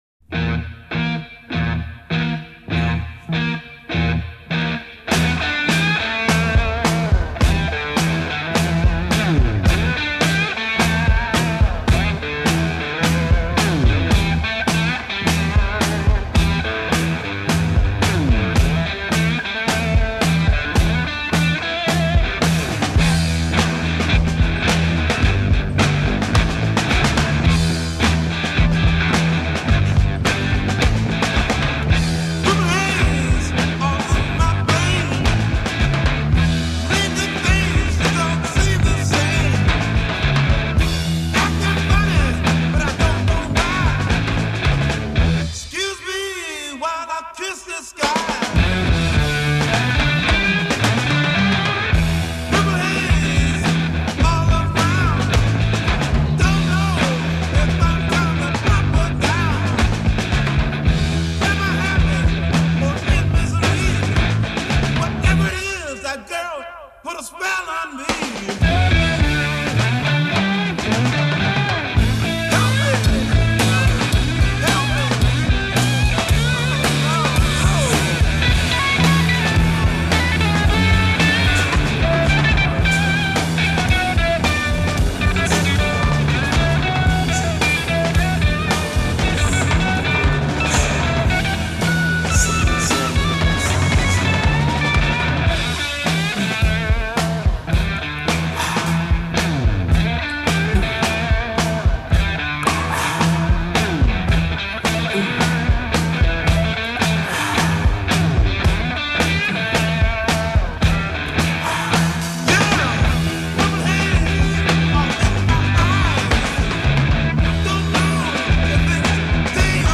Recorded at Olympic Sound Studios, London, 11 January 1967.
lead vocal & guitar
vocal and bass guitar
drums
Introduction 4 Guitar and bass, leaping major seconds!
"Haze Theme" 16 Single-note guitar solo over bass and drums.
Verse 15 + 3 Solo vocal over backing track. a
Transatlantic Psychedelic Blues